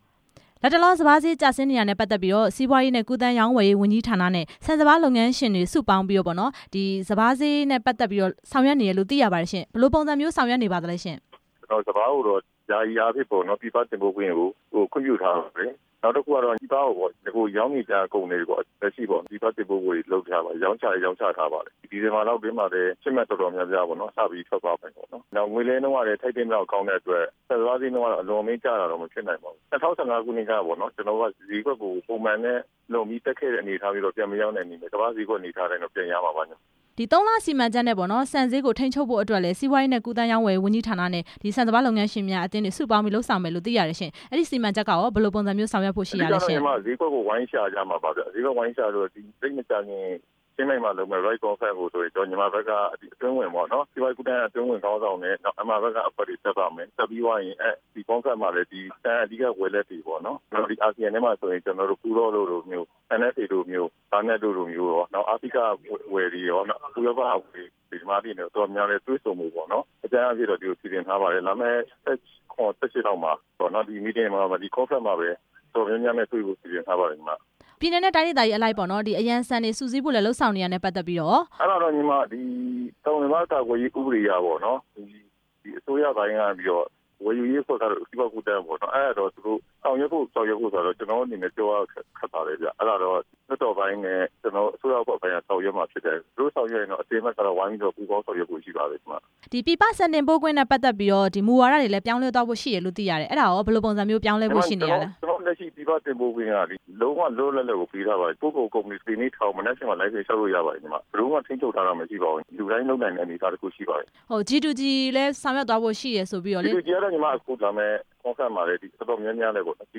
ပြည်တွင်းစပါးဈေး ကျဆင်းနေတဲ့အကြောင်း မေးမြန်းချက်